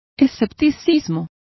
Complete with pronunciation of the translation of scepticism.